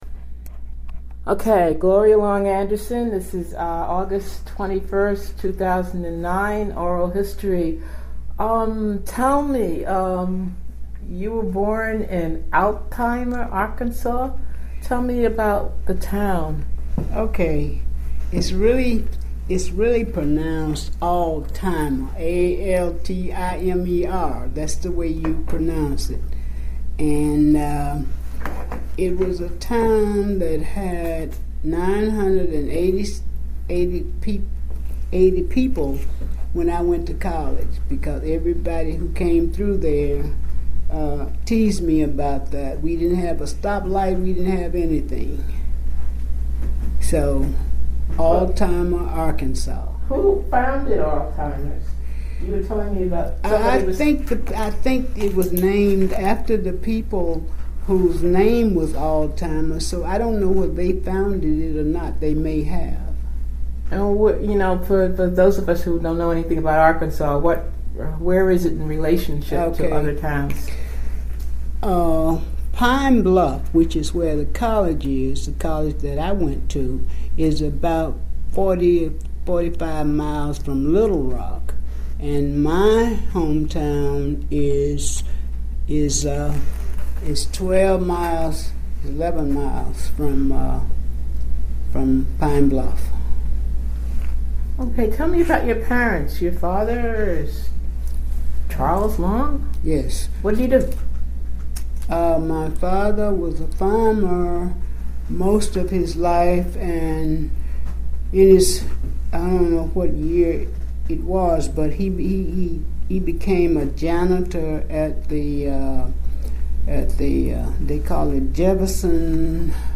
Oral histories